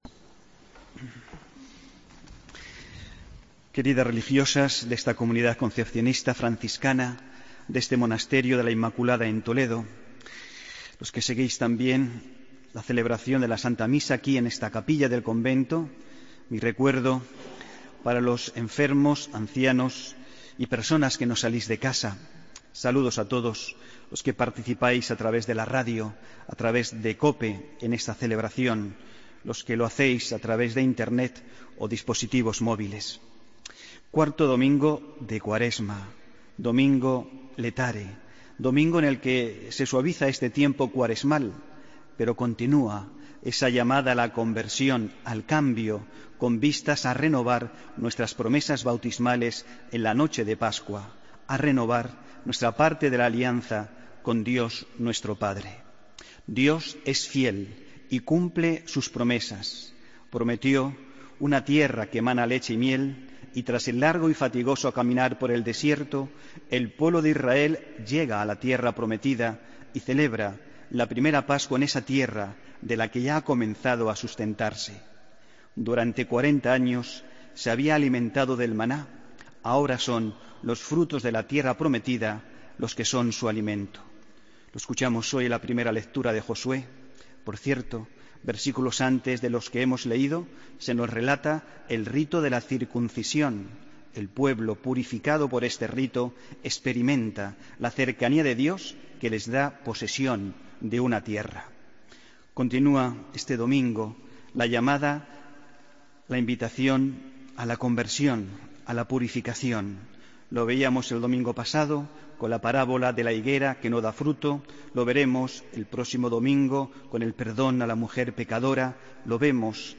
Homilía del domingo 6 de marzo de 2016